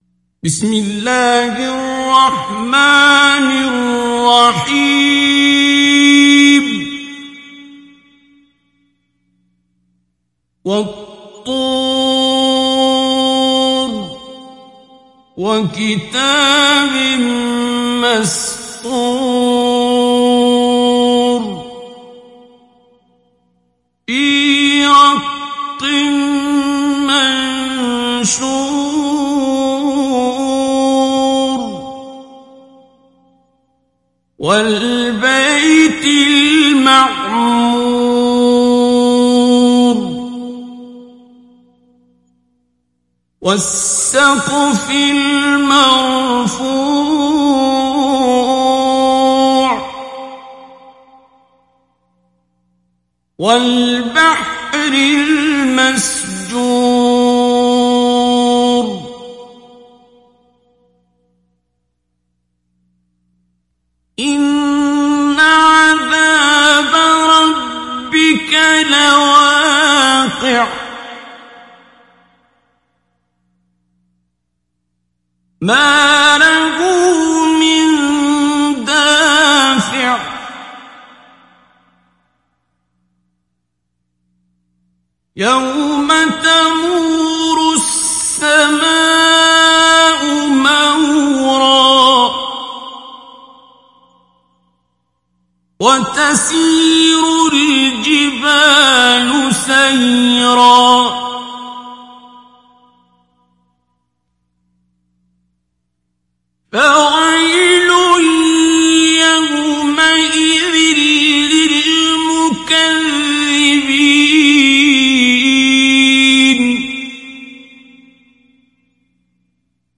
Télécharger Sourate At Tur Abdul Basit Abd Alsamad Mujawwad